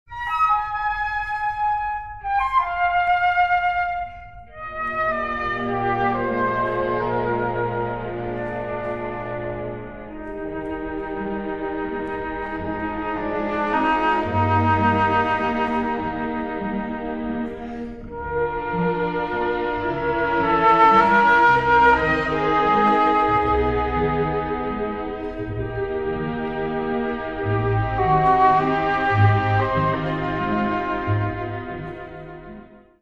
LP Restoration (After)
LPRESTORATION(AFTER).mp3